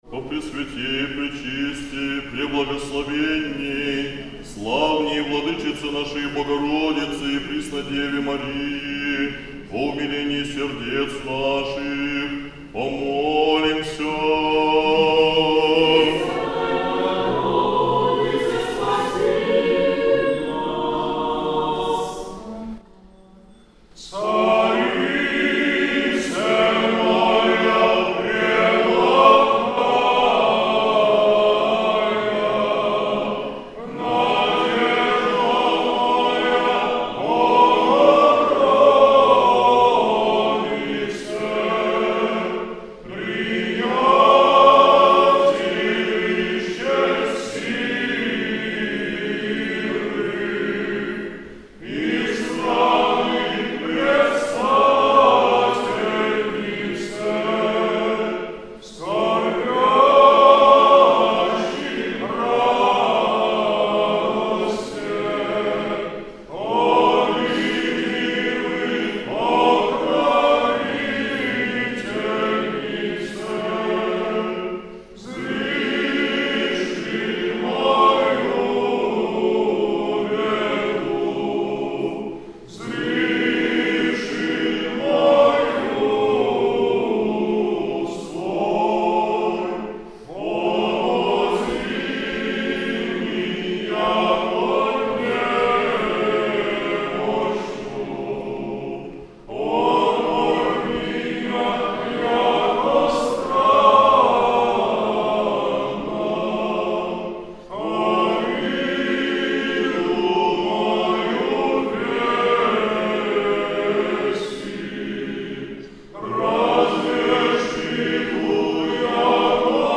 Накануне празднования Похвалы Пресвятой Богородицы митрополит Игнатий совершил вечернее богослужение в храме Покрова на Торгу
15 апреля, накануне Субботы Акафиста, Похвалы Пресвятой Богородицы, митрополит Вологодский и Кирилловский Игнатий совершил чин утрени с чтением акафиста Пресвятой Богородице в храме Архиерейского подворья Покрова на Торгу г.Вологды.
По окончании чтения Акафиста все присутсвующие в храме пропели молитву "Царица, Моя Преблагая..."